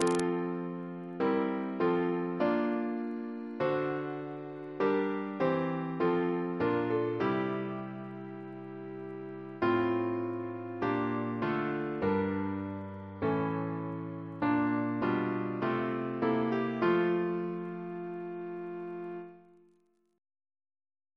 Double chant in F Composer: Francis E. Gladstone (1845-1928) Reference psalters: ACB: 248